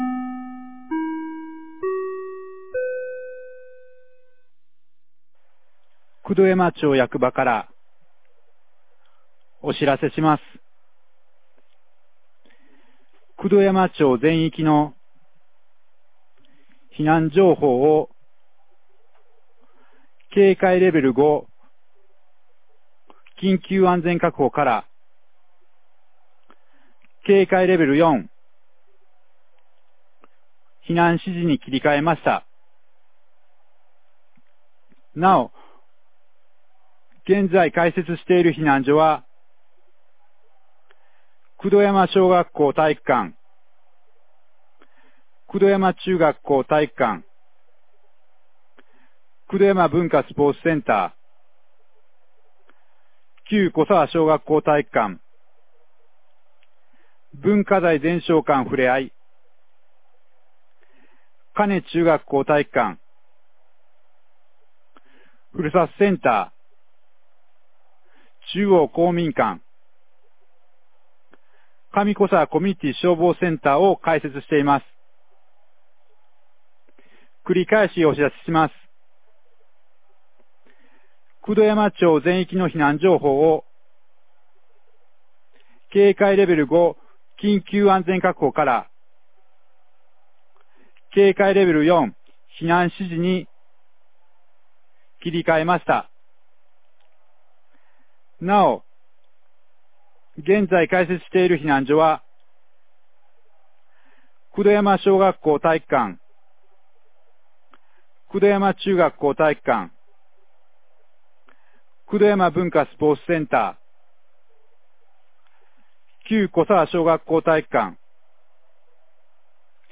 2023年06月02日 19時04分に、九度山町より全地区へ放送がありました。